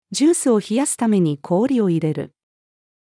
ジュースを冷やすために氷を入れる。-female.mp3